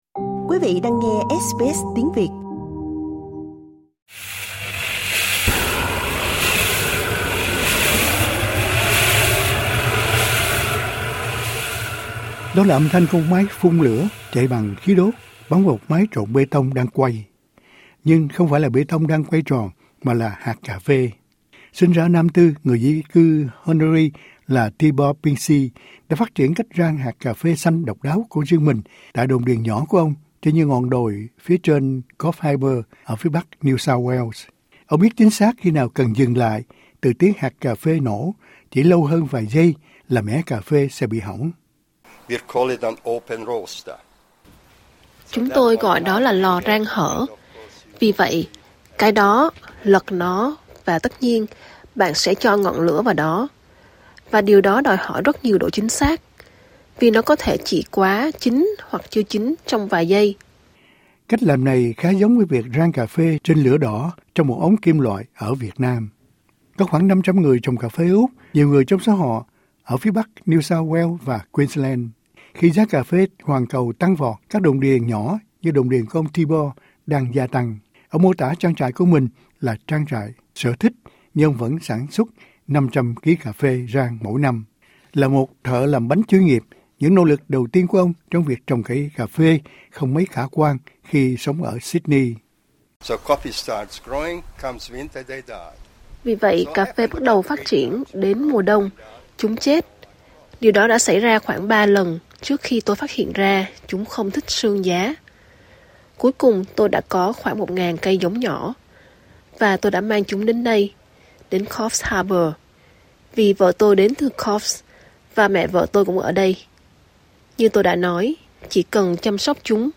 READ MORE SBS Việt ngữ Đó là âm thanh của một máy phun lửa chạy bằng khí đốt, bắn vào một máy trộn bê tông đang quay.